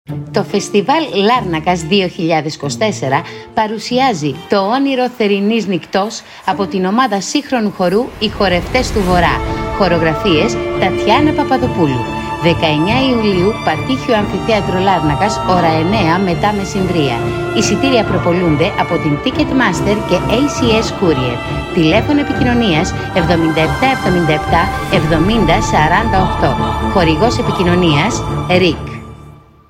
Audio & TV Spot